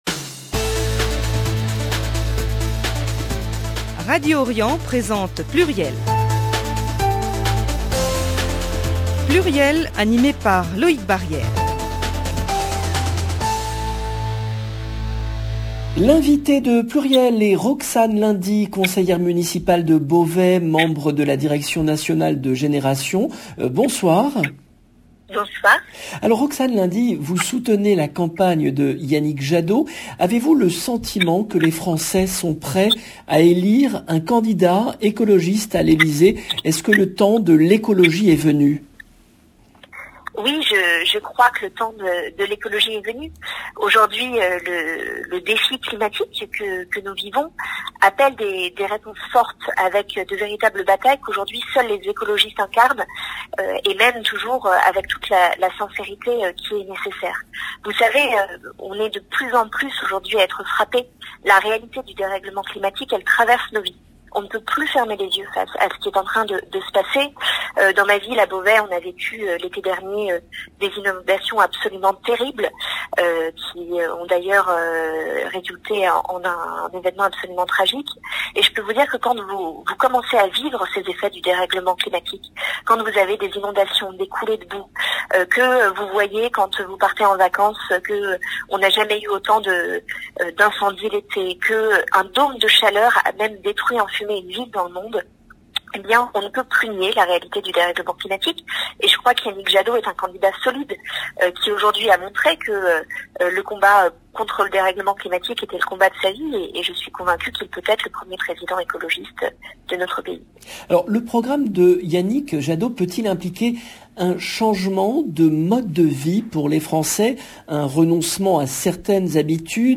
L’invitée de PLURIEL est Roxane Lundy, conseillère municipale de Beauvais, membre de la direction nationale de Génération•s